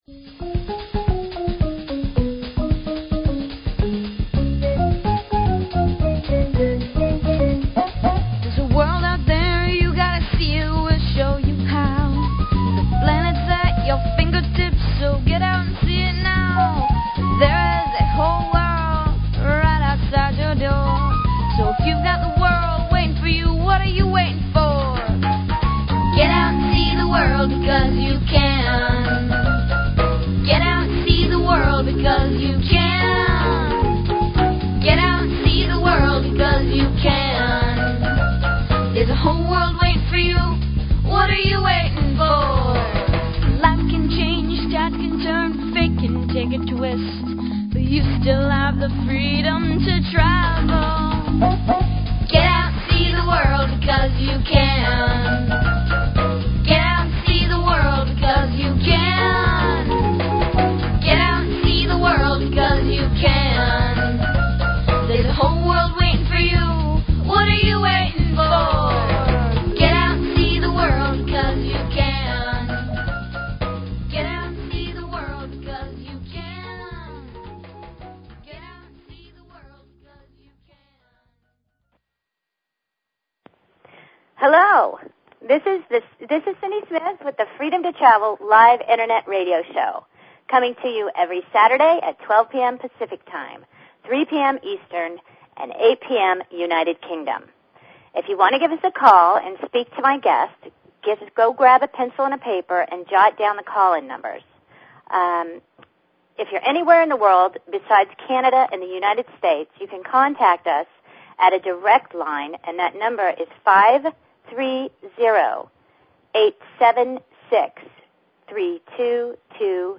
Talk Show Episode, Audio Podcast, Freedom_To_Travel and Courtesy of BBS Radio on , show guests , about , categorized as